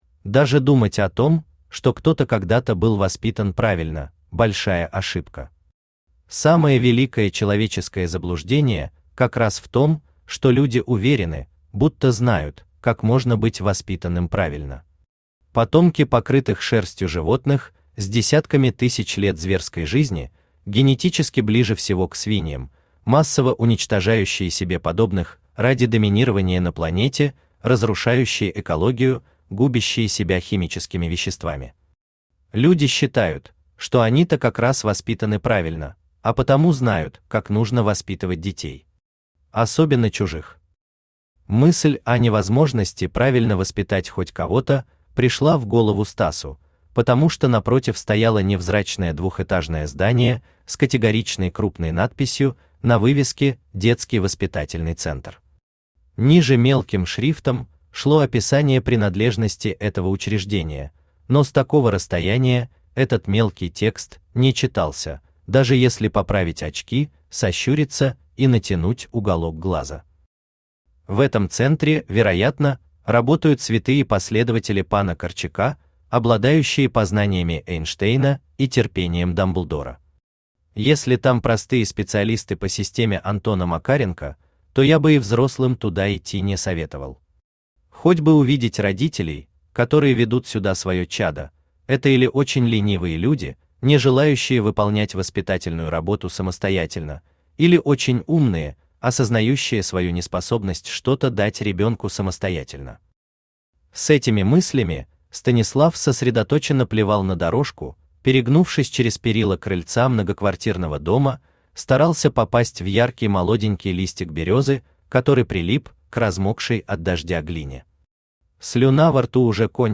Аудиокнига Бабник | Библиотека аудиокниг
Aудиокнига Бабник Автор Антон Николаевич Геля Читает аудиокнигу Авточтец ЛитРес.